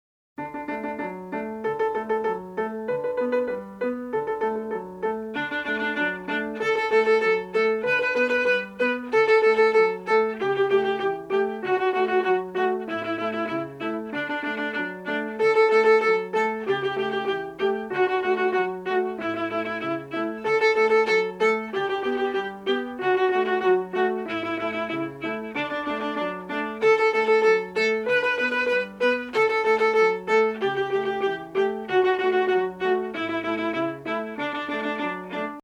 Piano Accompaniment Level